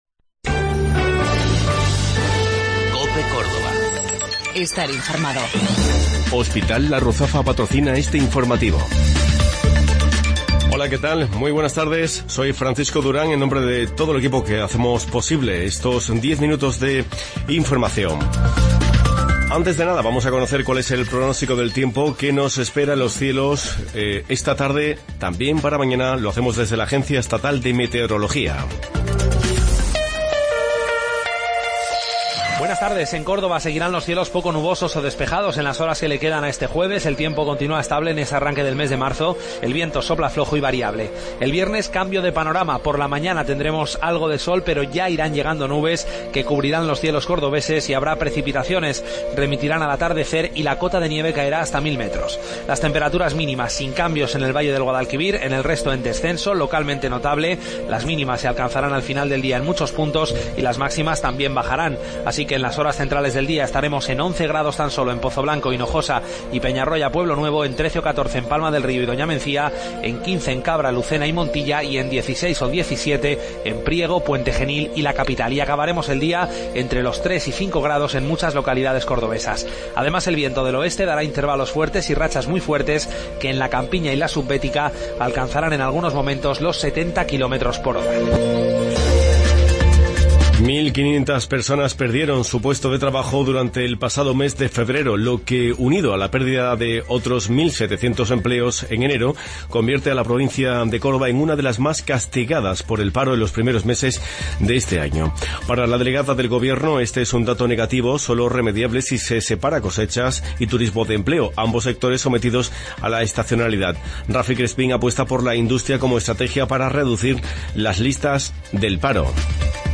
Mediodía en Cope. Informativo local 2 de Marzo 2017